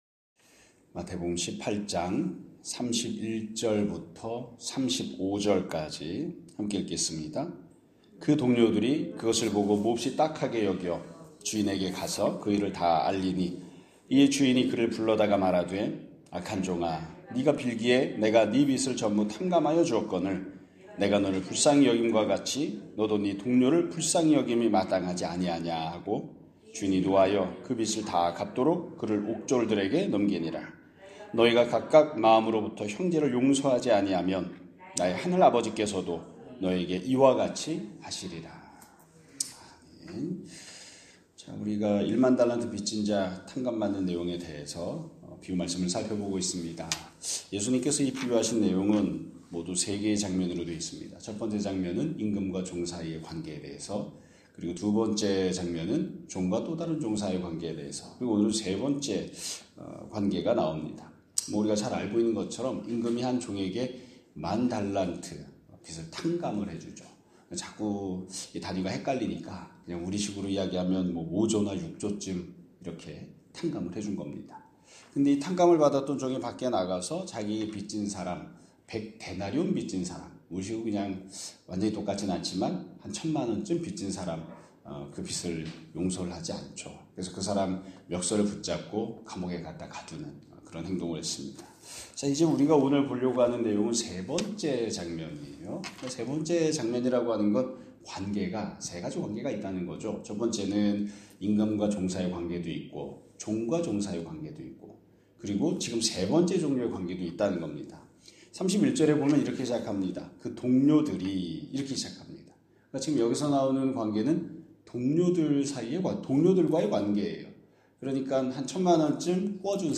2025년 12월 24일 (수요일) <아침예배> 설교입니다.